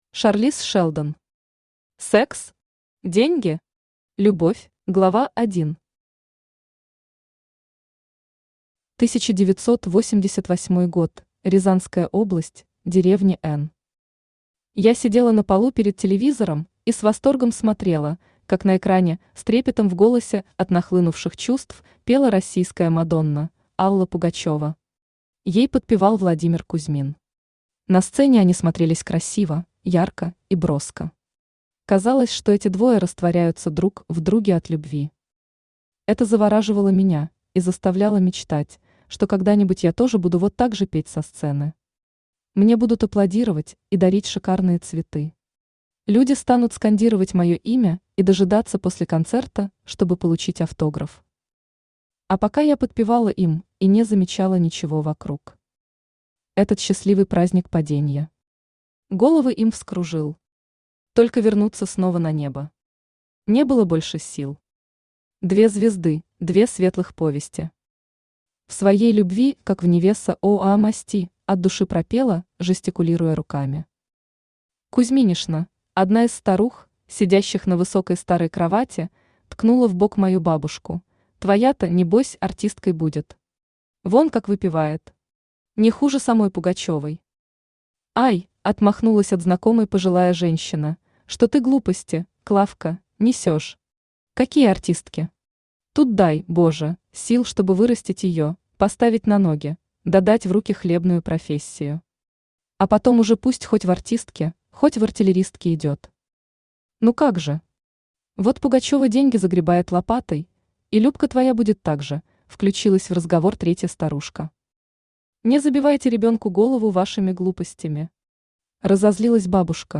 Aудиокнига Секс? Деньги? Любовь! Автор Шарлиз Шелдон Читает аудиокнигу Авточтец ЛитРес.